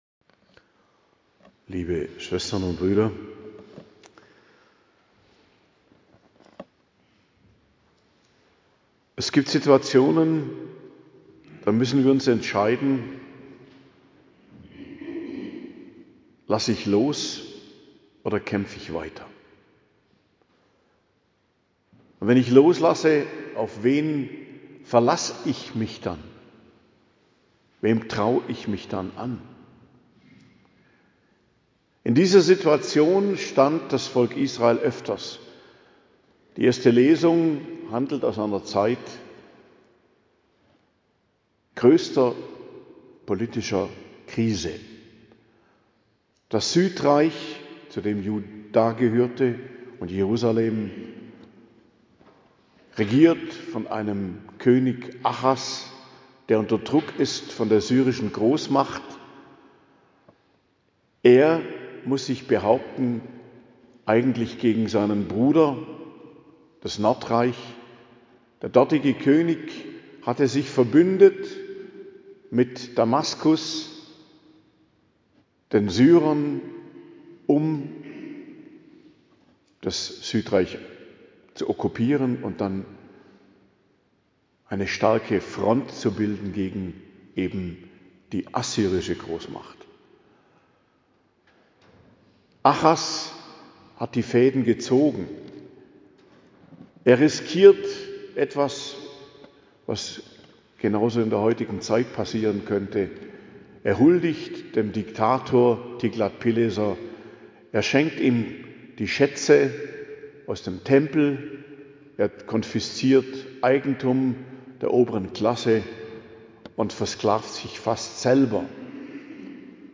Predigt zum 4. Adventssonntag, 21.12.2025